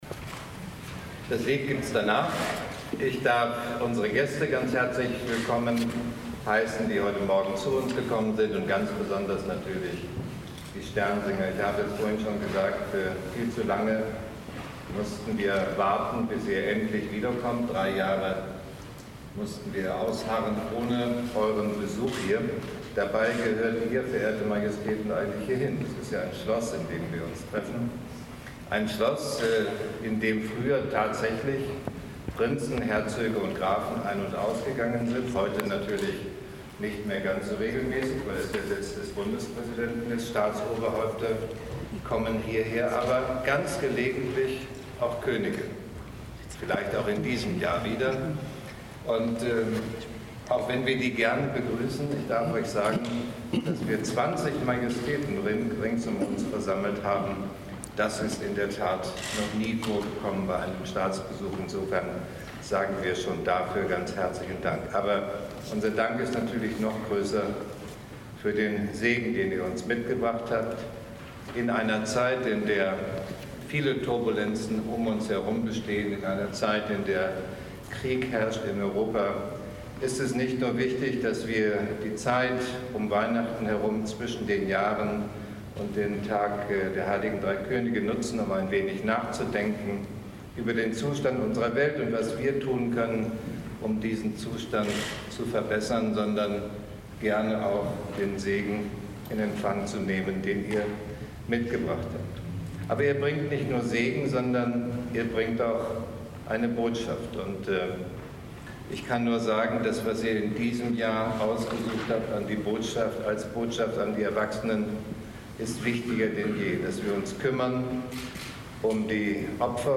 2_Bundespräsident_Rede
Bundespräsident Steinmeier empfing zum fünften Mal Sternsinger in seinem Amtssitz
Buprae_Rede.mp3